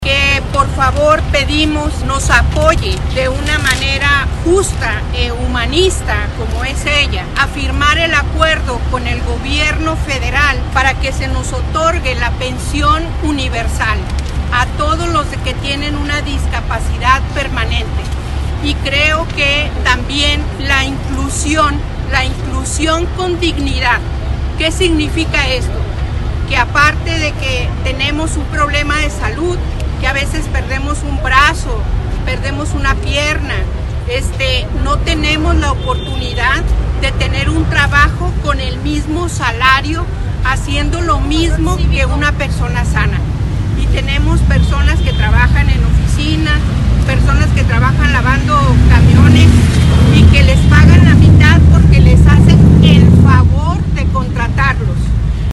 La mañana de este miércoles se manifestaron a las afueras de palacio de Gobierno un grupo de personas con discapacidad, para exigir que firme el acuerdo que tiene como objetivo otorgar una pensión de forma universal para ellos.
Una de las inconformes señaló que a diario las personas con discapacidad enfrentan descremación y problemas para conseguir empleo, aunado a que sus gastos médicos son más costosos.
MANIFESTACION-VS-MARU-.mp3